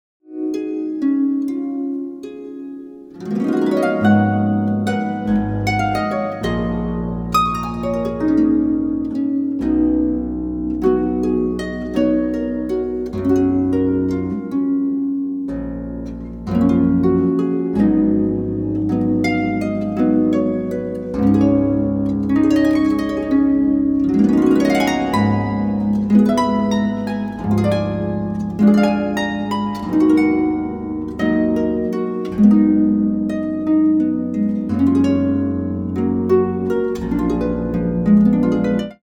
Is a collection of romantic and exotic pieces
Consisting primarily of harp solos